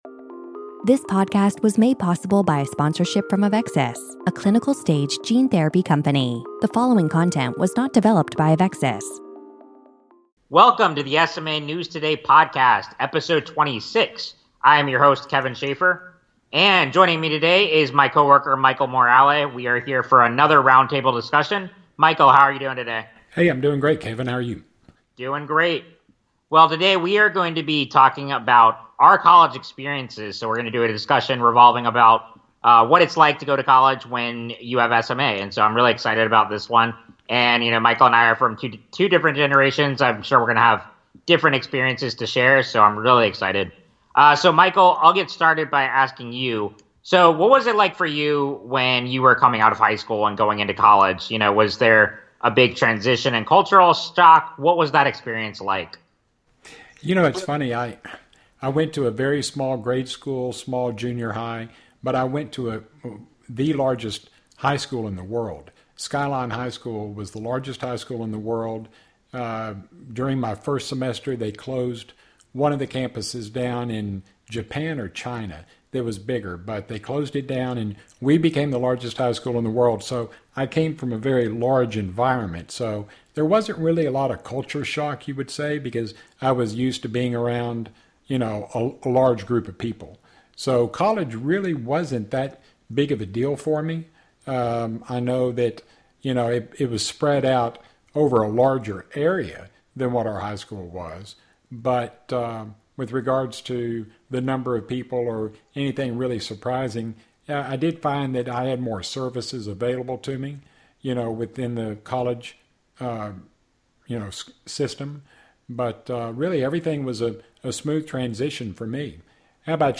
#28 Roundtable Discussion - Going To College If You Have SMA